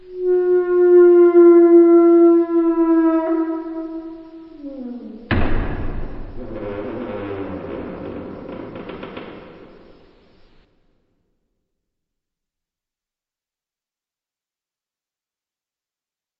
SFX音效 " 门吱吱作响 02 2
描述：使用数字录音机录制并使用Audacity进行处理
标签： 关闭 合页 铰链 吱吱 吱吱 吱吱 吱吱 开放性 处理 关闭 木材 弹响 摇摇欲坠 打开 关闭 吱吱 踩住 生锈
声道立体声